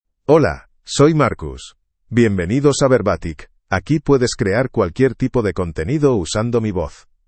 MaleSpanish (Spain)
MarcusMale Spanish AI voice
Marcus is a male AI voice for Spanish (Spain).
Voice sample
Listen to Marcus's male Spanish voice.
Marcus delivers clear pronunciation with authentic Spain Spanish intonation, making your content sound professionally produced.